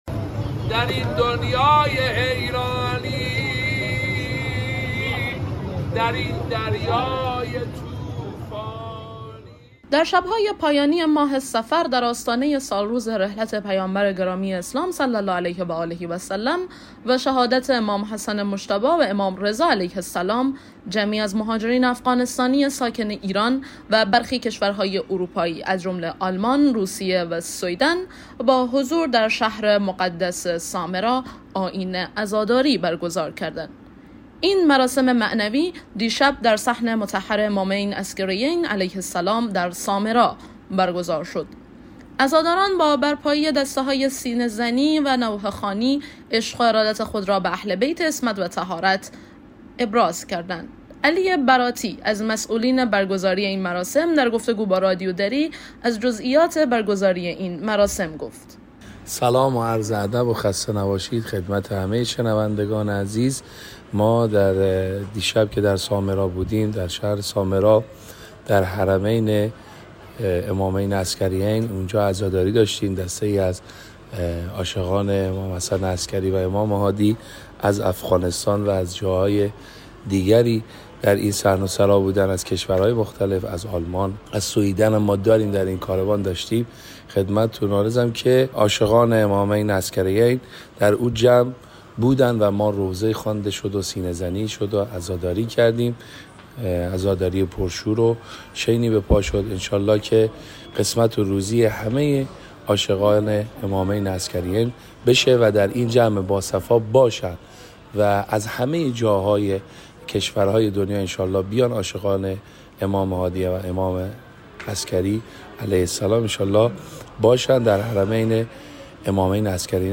عزاداران با برپایی دسته‌های سینه‌زنی و نوحه‌خوانی، عشق و ارادت خود را به اهل بیت عصمت و طهارت (ع) ابراز کردند.
مراسم با قرائت زیارت و مرثیه‌سرایی ادامه یافت و در پایان، همه عزاداران به زیارت امامین عسگریین مشرف شدند.